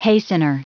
Prononciation du mot hastener en anglais (fichier audio)
Prononciation du mot : hastener